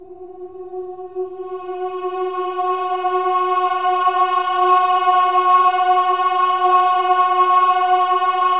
OH-AH  F#4-R.wav